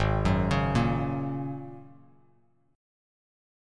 G#9sus4 chord